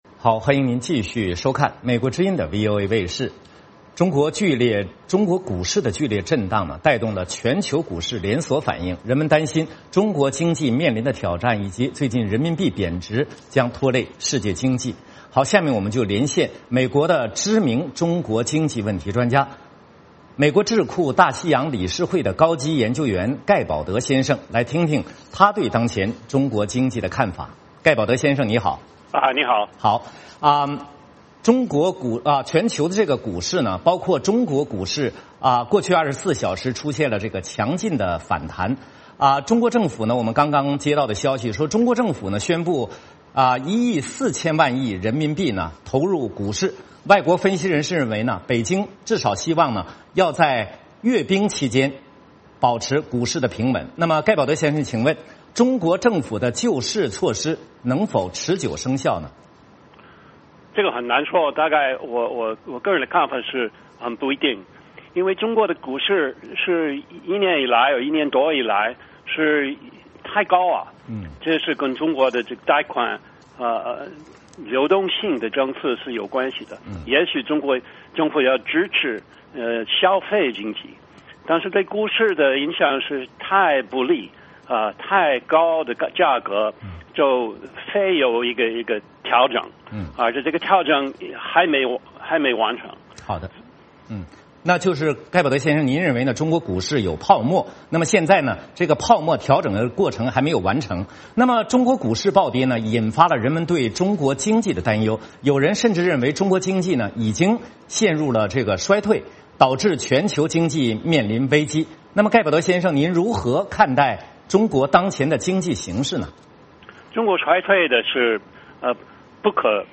VOA连线：中国经济仅仅是放缓还是已经陷入危机？